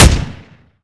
osiprShoot.ogg